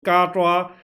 PLAY 嘎抓打哈欠